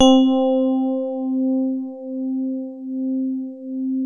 TINE HARD C3.wav